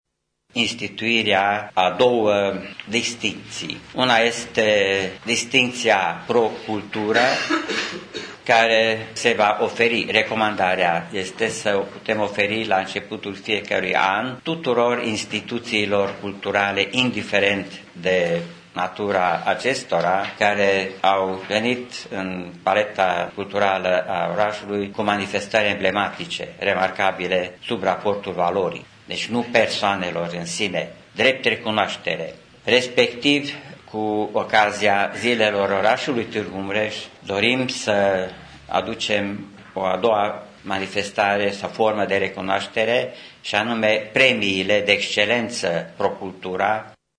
Consilierul local UDMR Tîrgu-Mureş, Kikeli Pal: